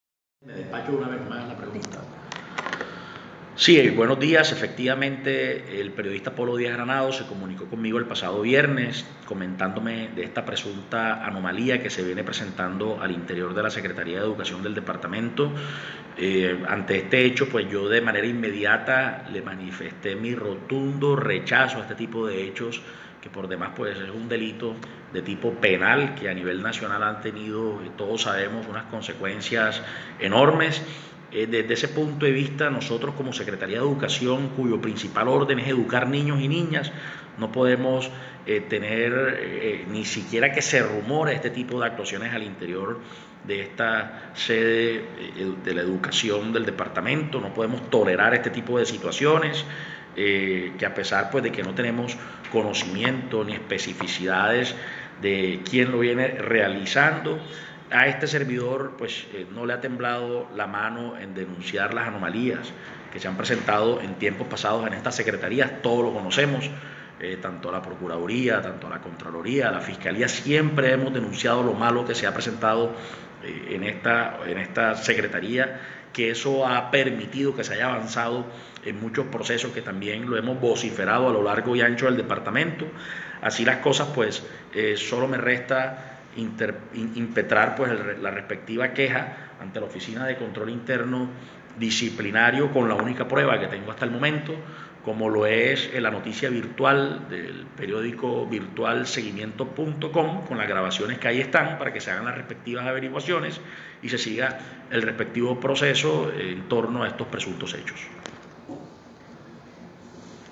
Declaración de Eduardo Arteta Coronell